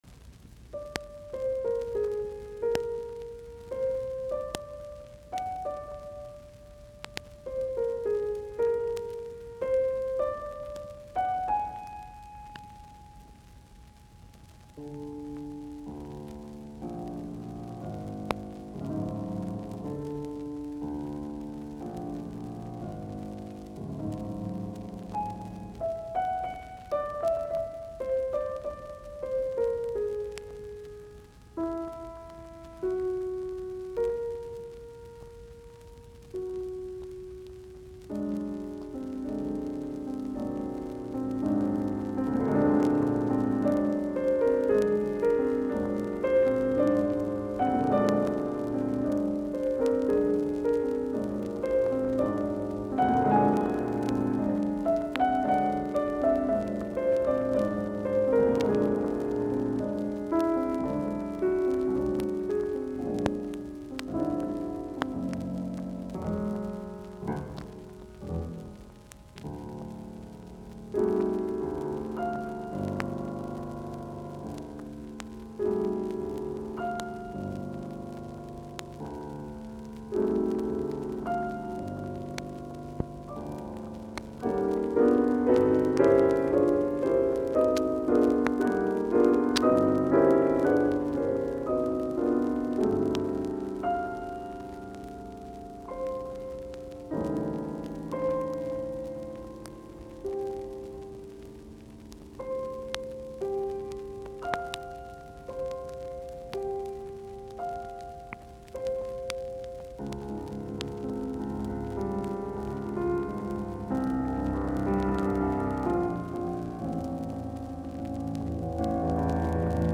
Soitinnus : Piano, 4-kät.